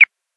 PixelPerfectionCE/assets/minecraft/sounds/mob/bat/idle4.ogg at ca8d4aeecf25d6a4cc299228cb4a1ef6ff41196e